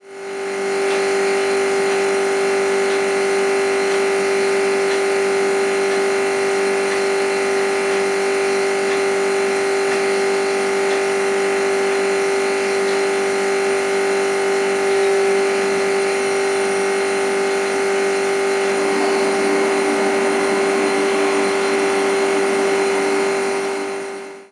Motor de una nevera
Sonidos: Hogar